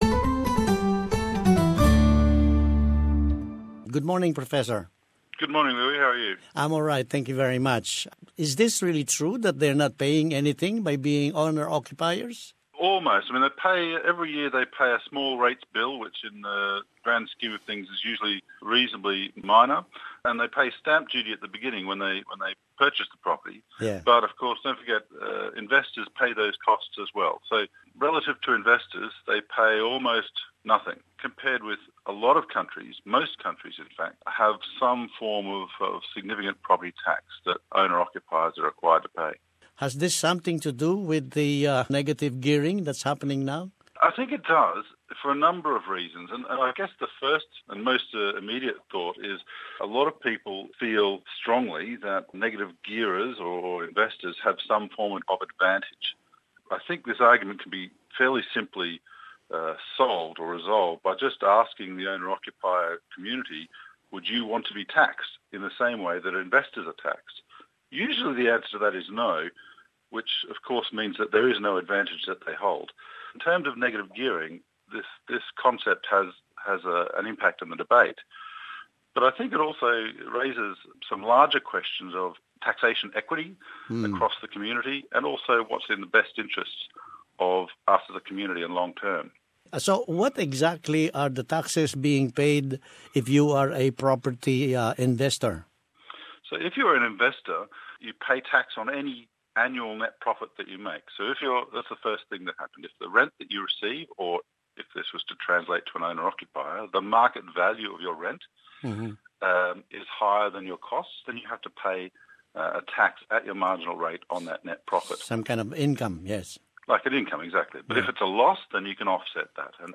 speaks with Professor